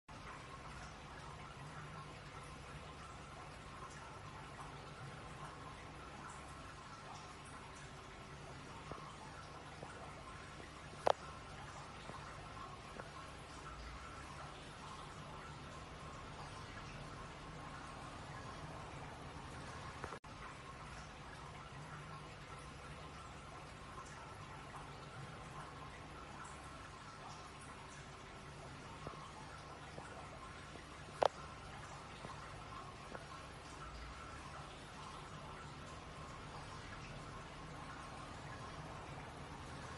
水声.mp3